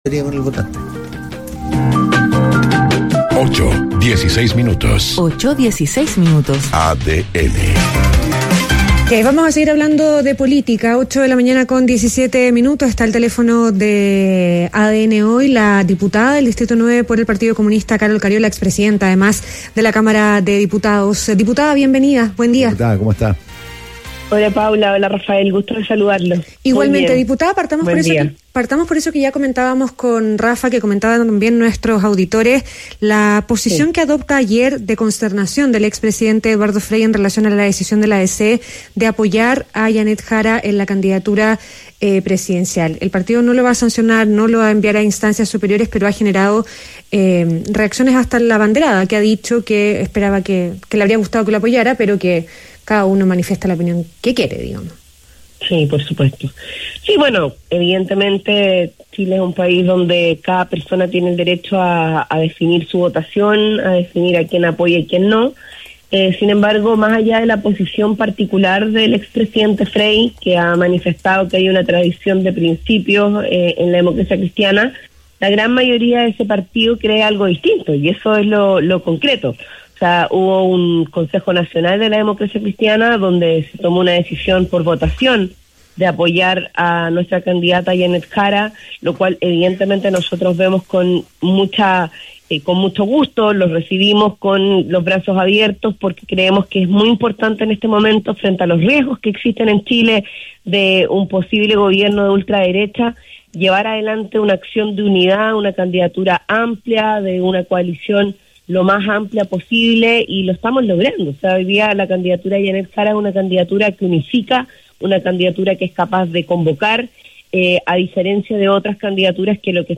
ADN Hoy - Entrevista a Karol Cariola